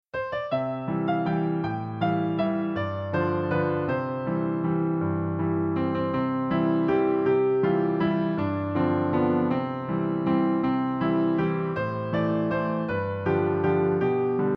Folk Song Lyrics and Sound Clip